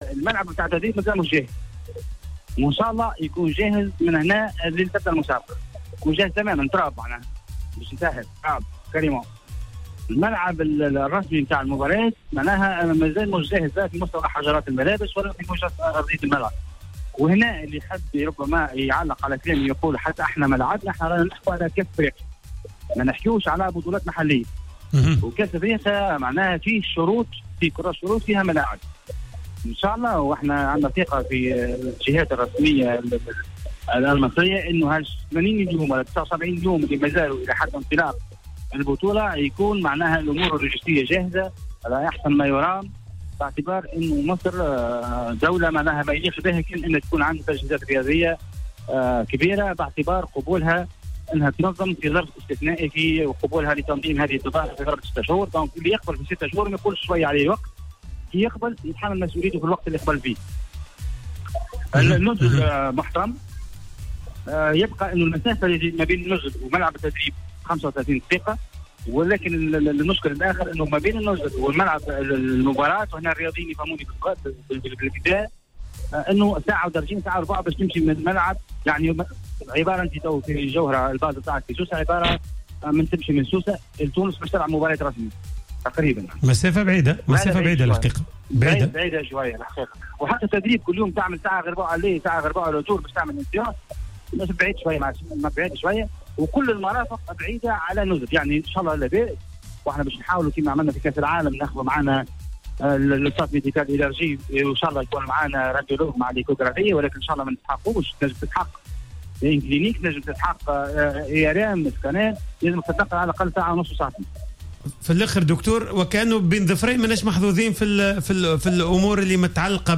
كشف رئيس الجامعة التونسية لكرة القدم الدكتور وديع الجريء لدى تدخله في حصة "Planète Sport" أن الجامعة ستعمل على تحسين جميع الظروف اللوجيستية للمنتخب خاصة بعد معاينة وفد الجامعة لجميع التفاصيل المتعلقة بوضعية الإقامة و مكان التدريبات خلال نهائيات كأس إفريقيا.